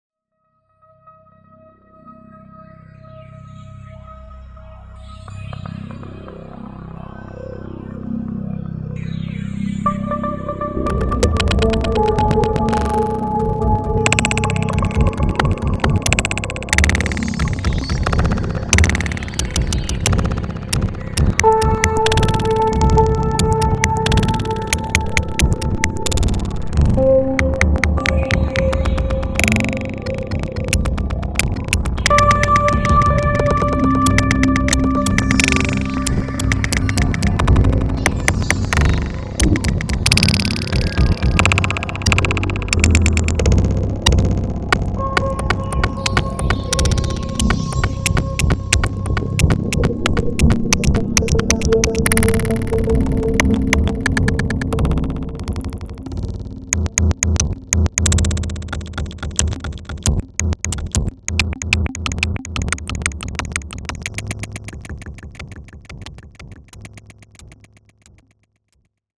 Akai Force and laptop sketch 01.